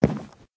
sounds / step / wood3.ogg
wood3.ogg